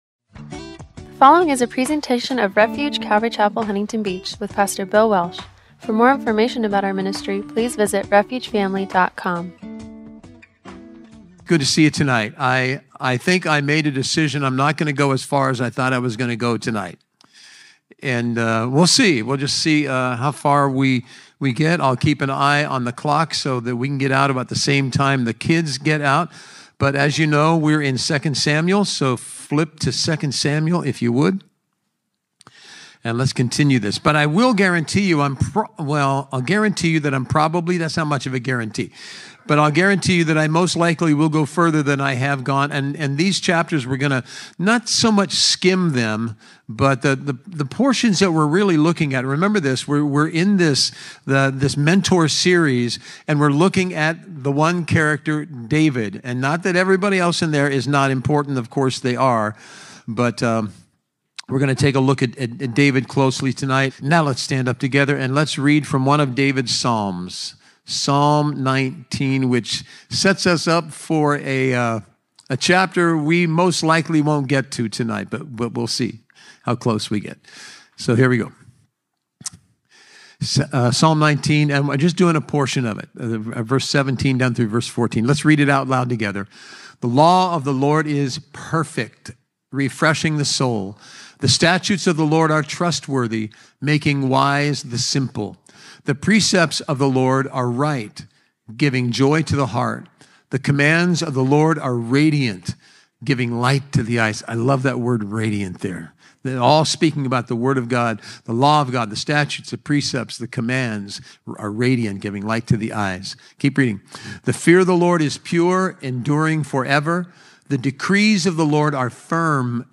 “The Mentor Series” – Audio-only Sermon Archive
Service Type: Wednesday Night